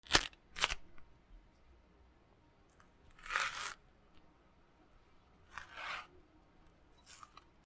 バナナの皮をむく